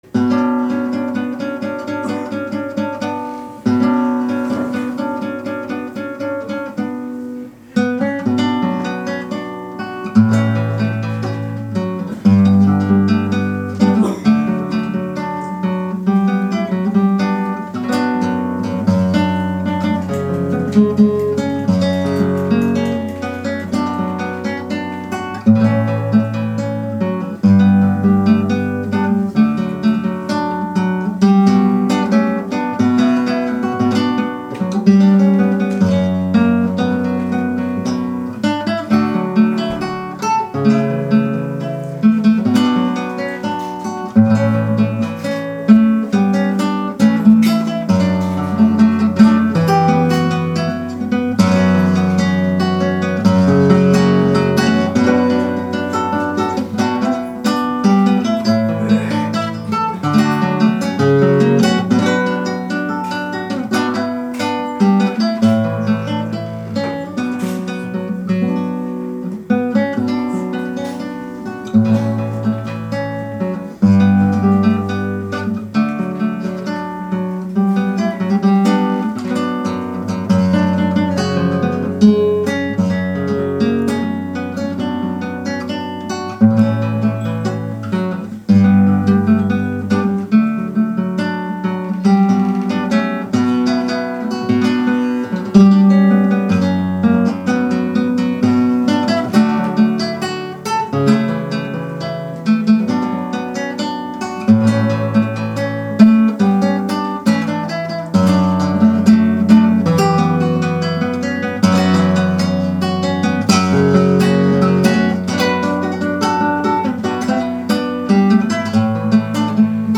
クラシック系のギター演奏を得意とし、ライブハウスに出没しているそうです。
ギター演奏